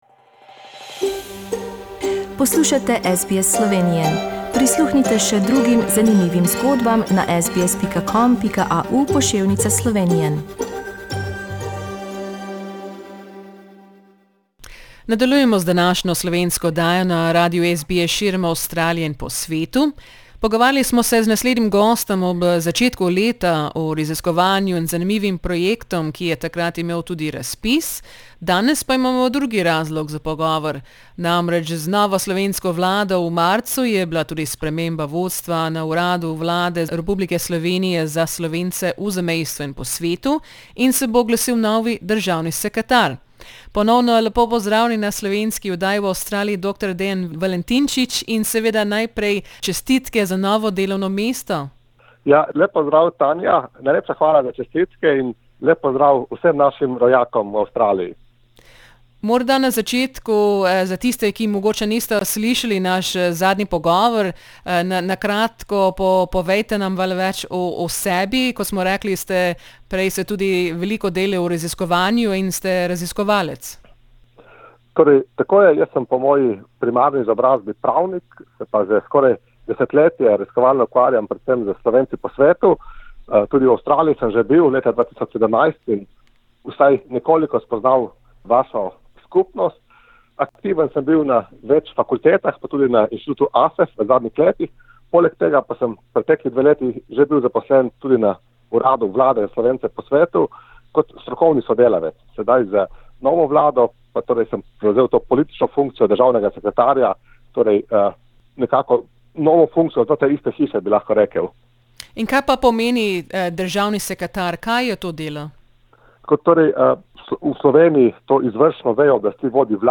Nova slovenska vlada je v mesecu marcu prinesla nekaj sprememb tudi v vodstvu Urada vlade Republike Slovenije za Slovence v zamejstvu in po svetu. Pogovarjali smo se z novim državnim sekretarjem urada, dr. Dejanom Valentinčičem.